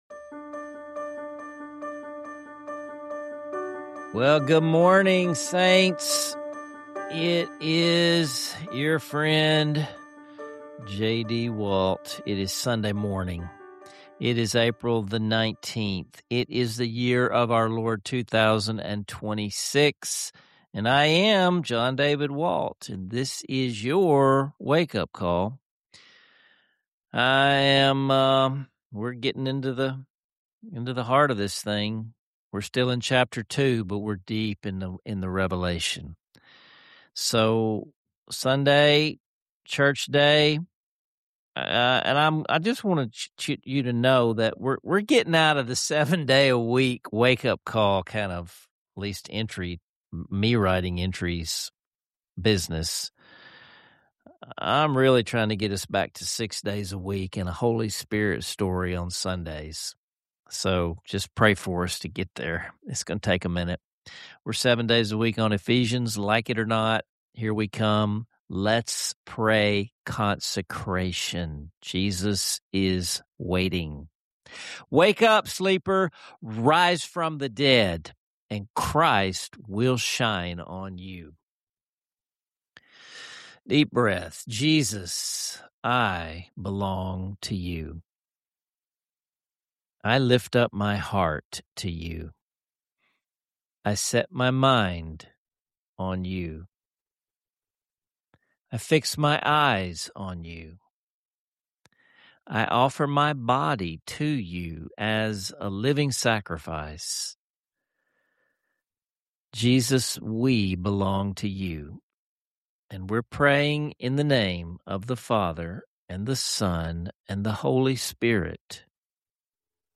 You’ll also hear moving hymns, honest journal prompts, and an invitation to participate in a revival of banding together.